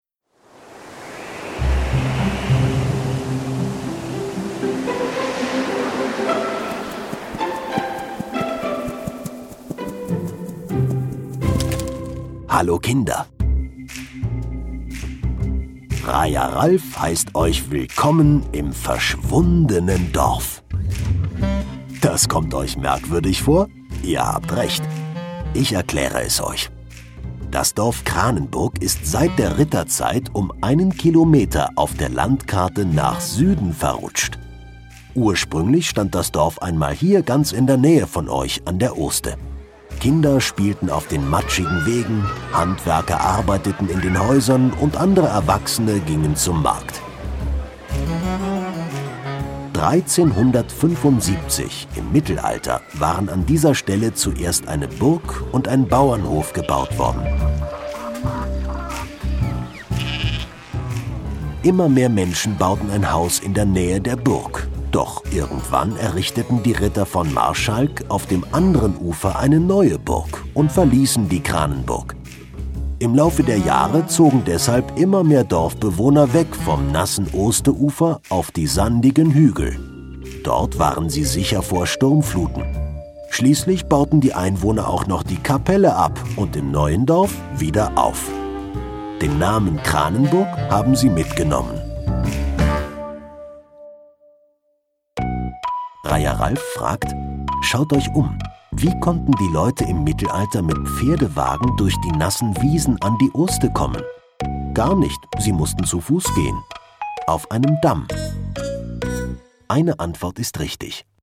Damm Kranenburg - verschwundenes Dorf - Kinder-Audio-Guide Oste-Natur-Navi